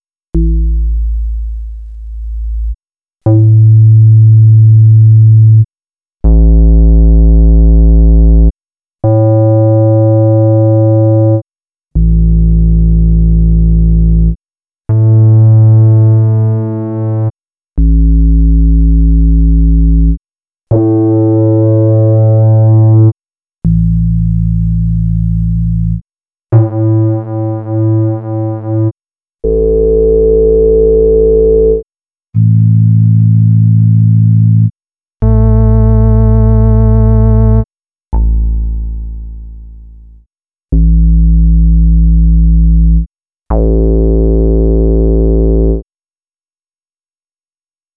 12_PulseBass.wav